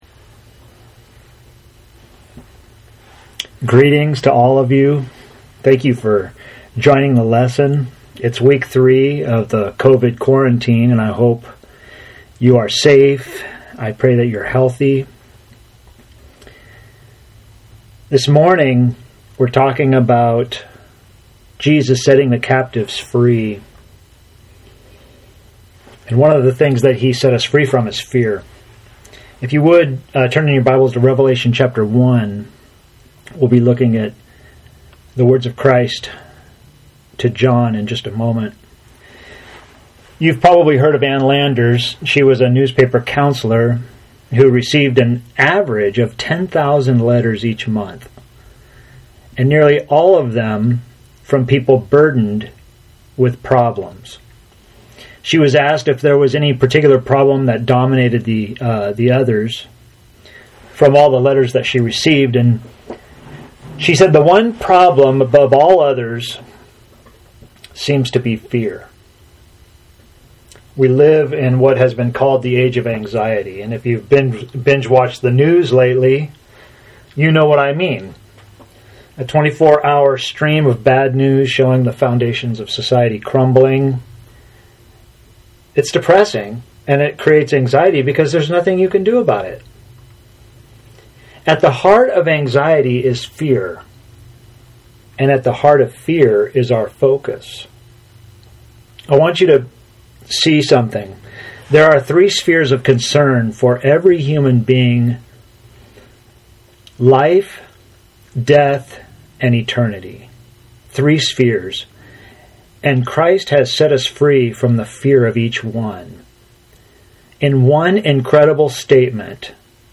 SERMON: Set Free From Fear (above).